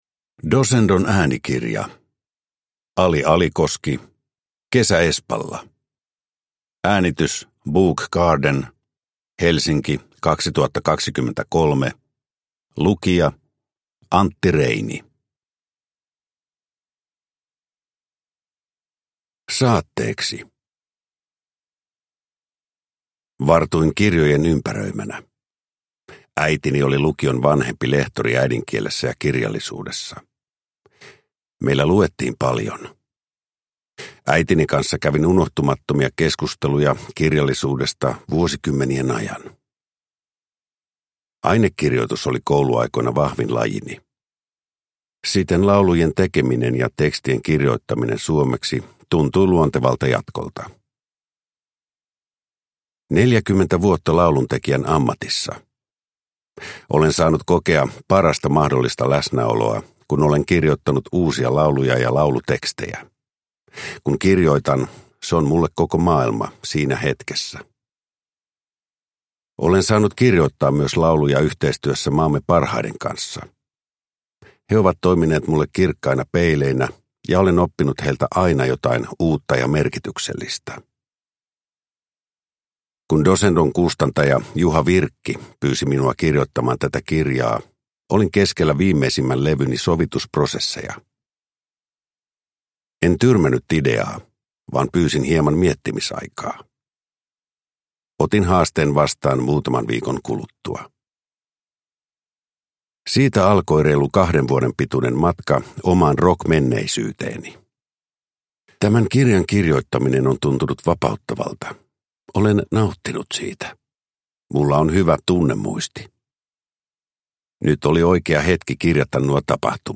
Kesä Espalla – Ljudbok – Laddas ner
Uppläsare: Antti Reini